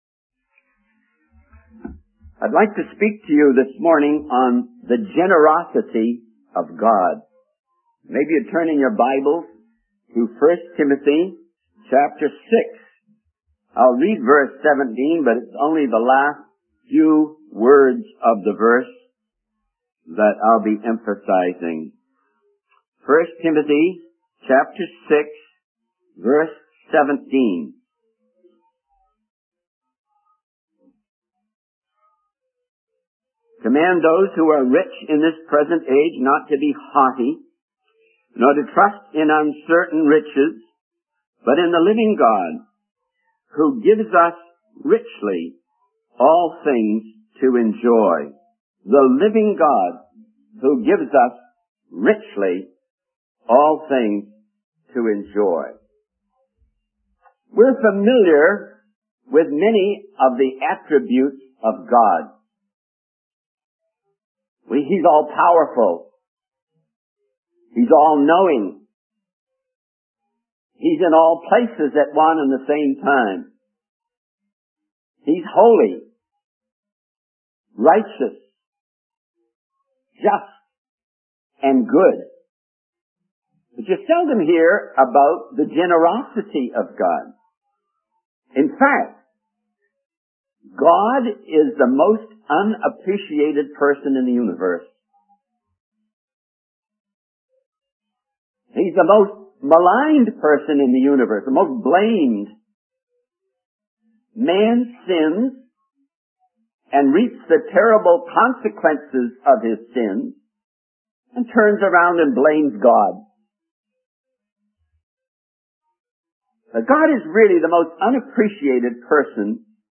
In this sermon, the speaker discusses the generosity of God in both nature and the spiritual realm. He emphasizes that God is often unappreciated despite his abundant blessings.